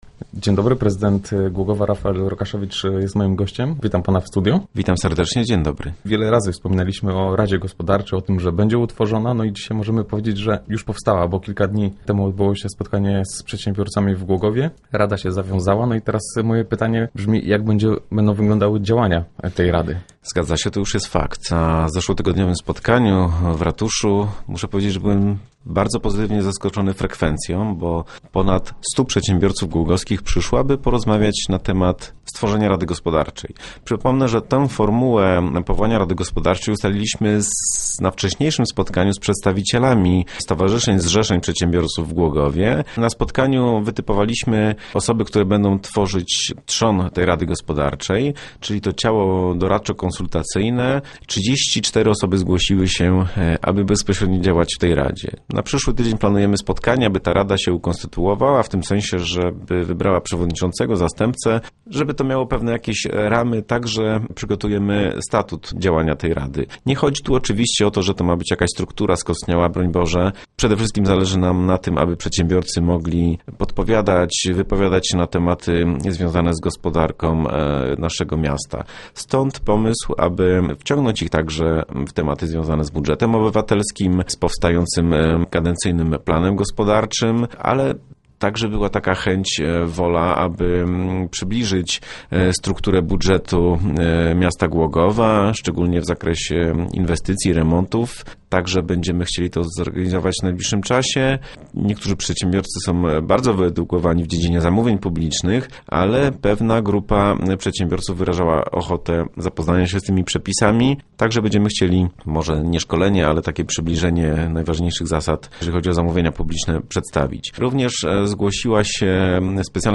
To tylko jeden z tematów poruszanych podczas rozmowy z prezydentem na antenie Radia Elka.